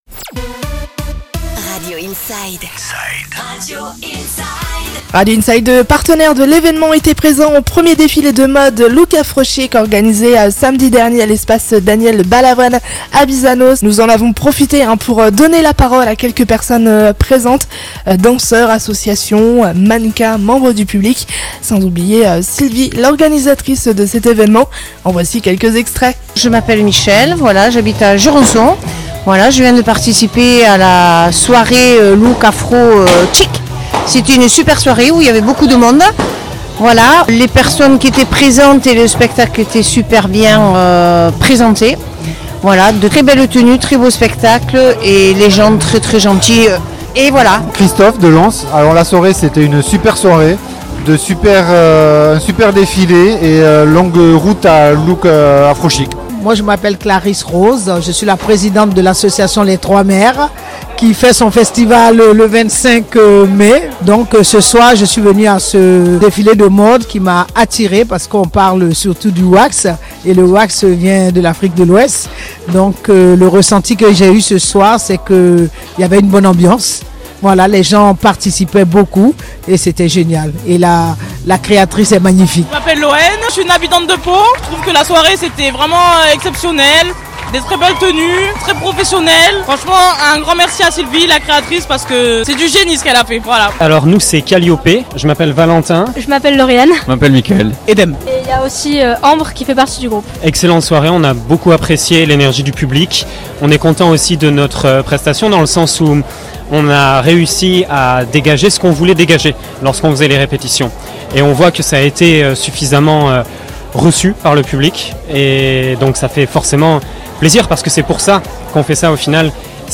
Interview du "défilé Look Afro Chic" du samedi 18 mai, à Bizanos sur Radio Inside